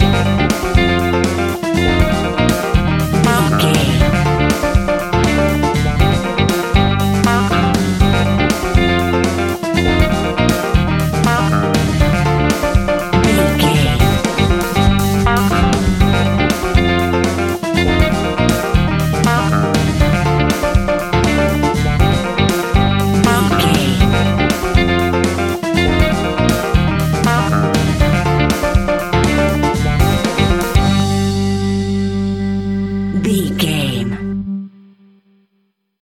Aeolian/Minor
latin
uptempo
drums
bass guitar
percussion
brass
saxophone
trumpet